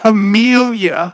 synthetic-wakewords
ovos-tts-plugin-deepponies_Discord_en.wav